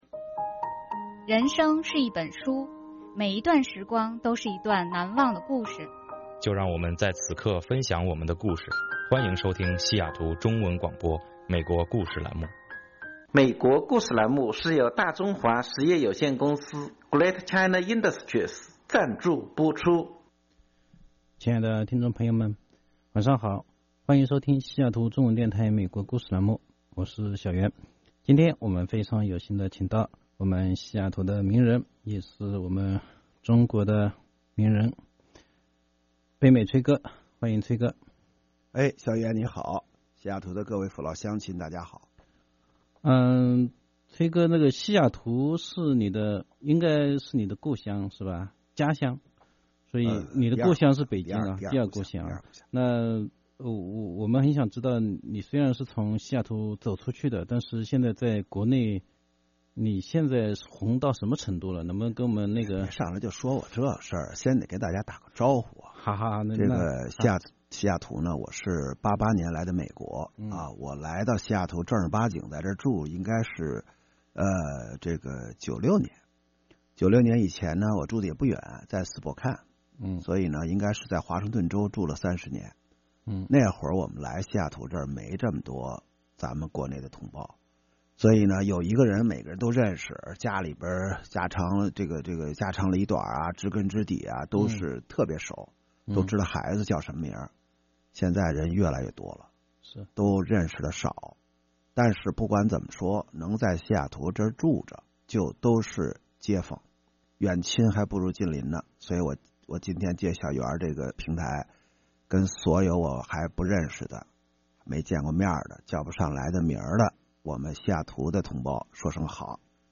7月5日，北美崔哥再次做客西雅图中文电台“美国故事”栏目，和我们一起分享他的精彩人生。
西雅图中文电台专访北美崔哥